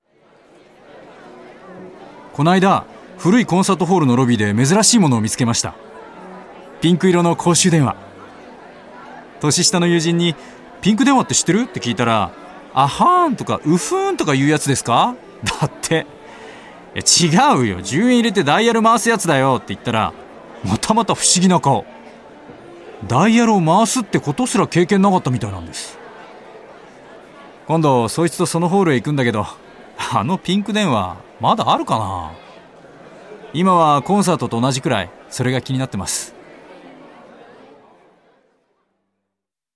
クリアで安定感のある二枚目ボイスはアニメやナレーションでも活躍。